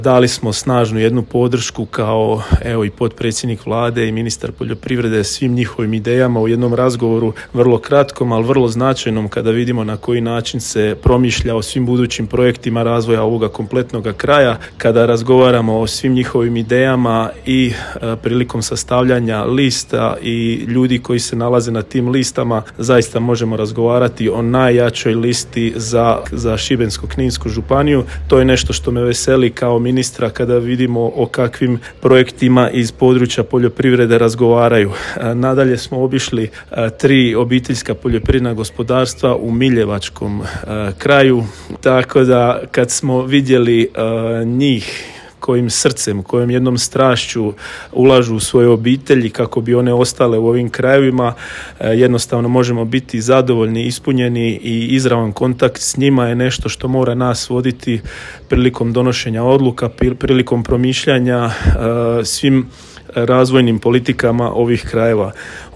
Za Radio Drniš je kazao: